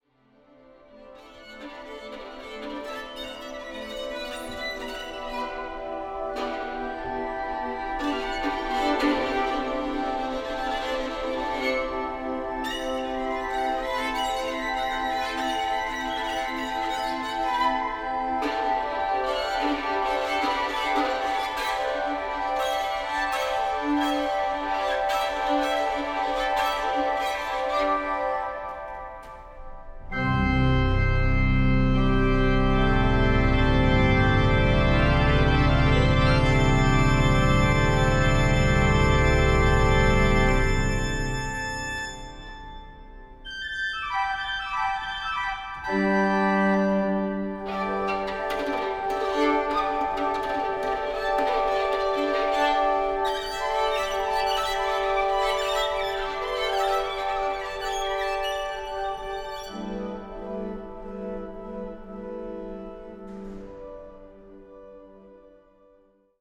for Violin and Organ
Organ